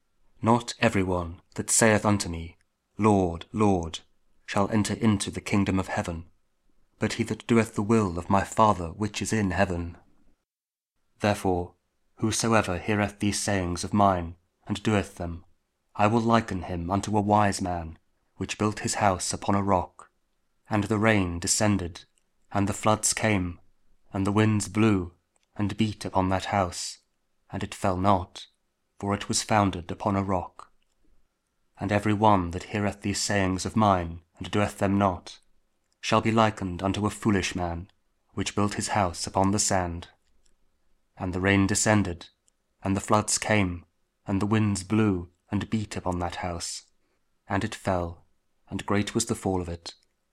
Matthew 7: 21, 24-27 | Daily Bible Verses, Advent, Thursday Week 1 | King James Audio Bible KJV